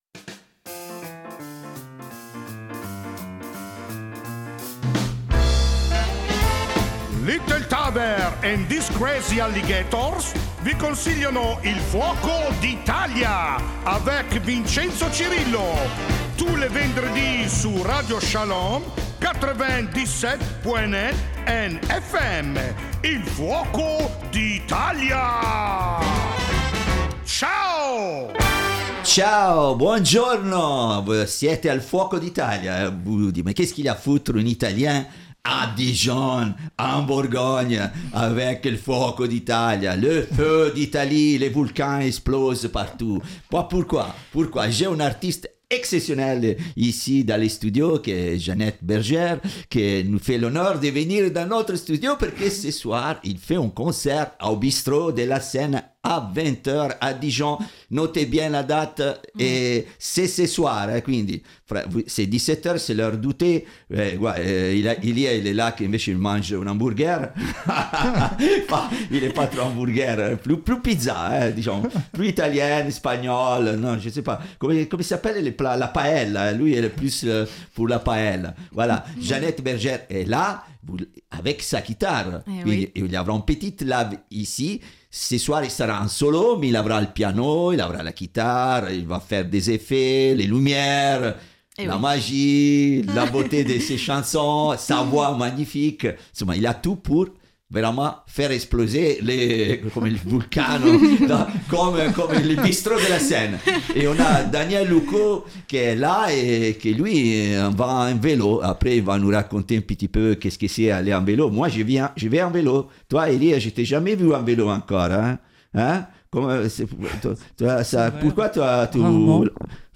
Aujourd'hui nous avions un grand soleil, en dehors et dans nos studios.
chanteuse & musicienne aux accents résolument soul, jazz & blues.. Munie de sa guitare, elle nous a fait le grand plaisir d'interpreter plusieurs titres en direct !